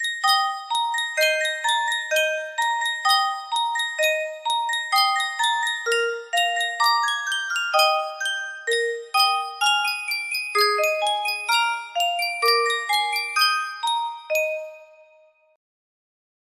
Sankyo Music Box - There Is a Tavern in the Town 8H music box melody
Full range 60